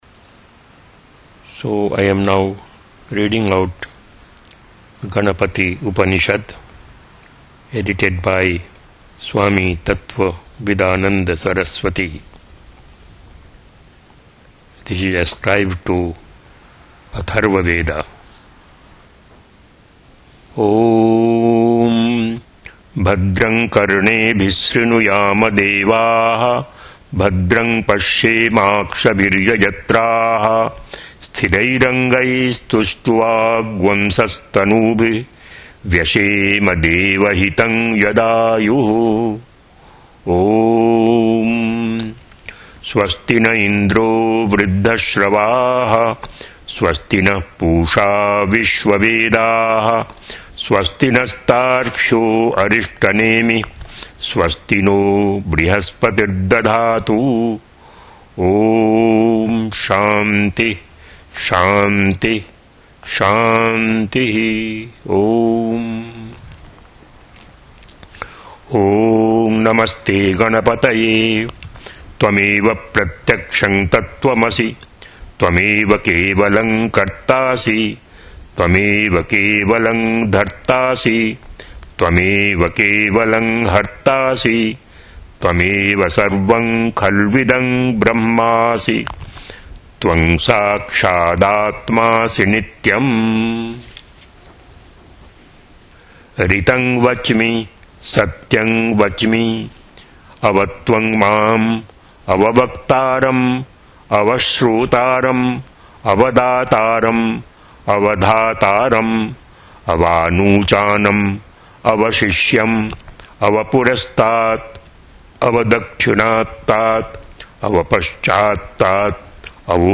Gaṇapati Upaniṣad, chanted Sanskrit text (mp3, 128kbs, 9:28, 9 MB)
chanting audio files